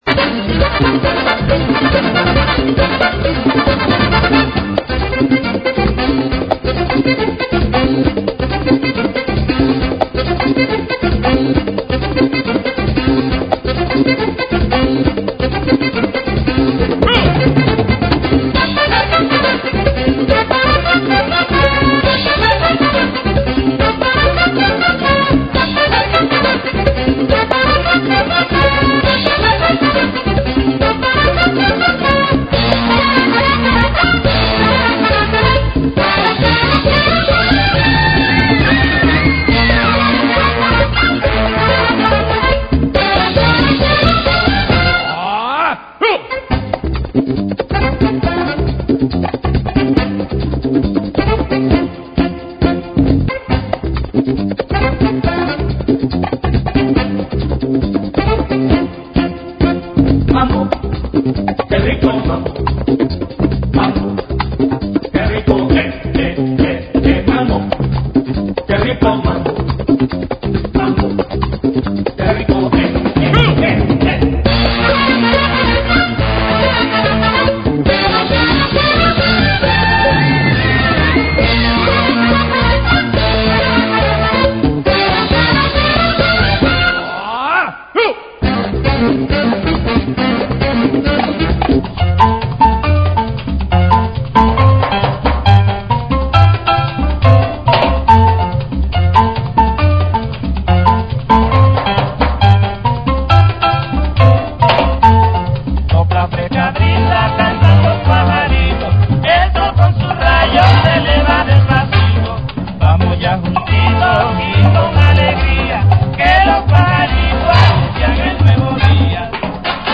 GENERO: CLASICAS DEL MAMBO
AEROBICS (STEP-HILOW)